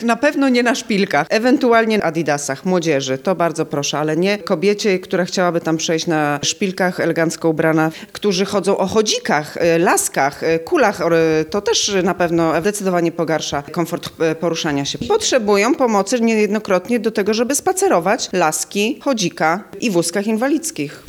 sonda starówka.mp3